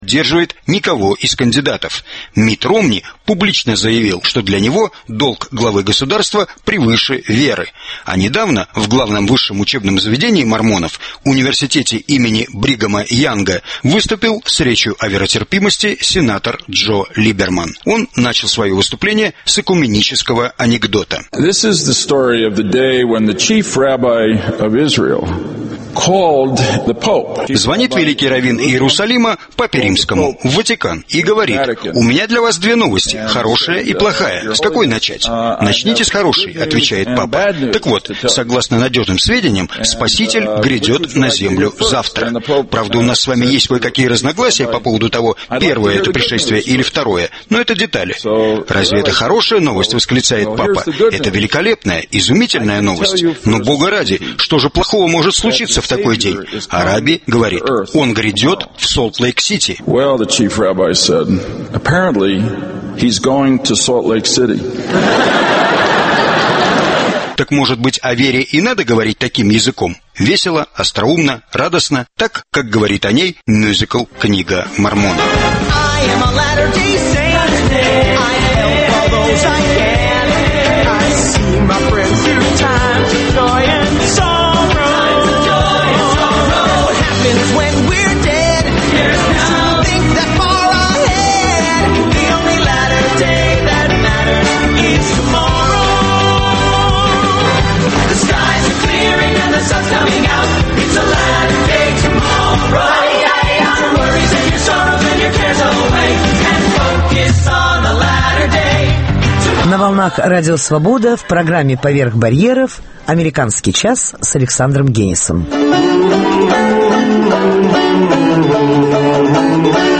Кеннан - идеолог Холодной войны (Беседа с Борисом Парамоновым)